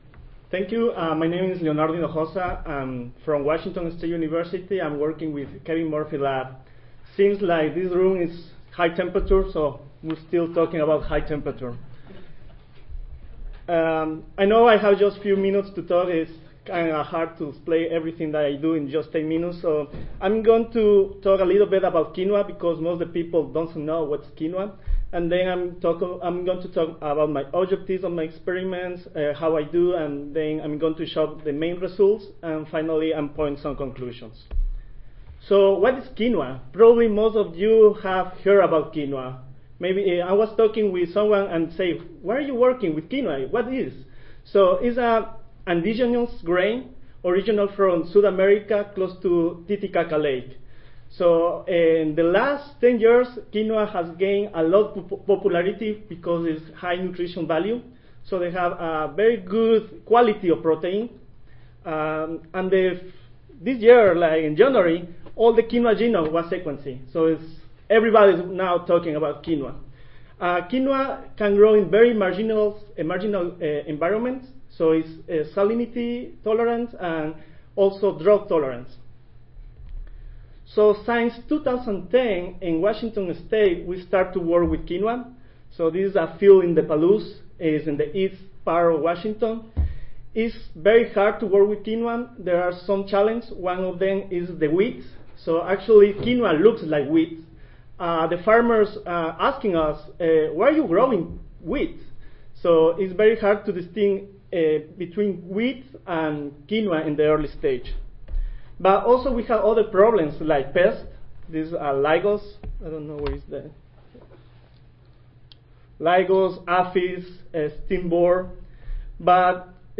Washington State University Audio File Recorded Presentation